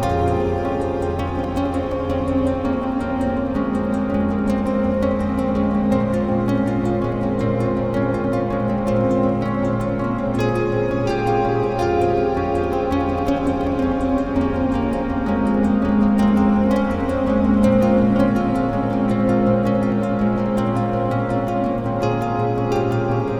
The resulting loop: Download it here.
006-ad-infinitum-loop.wav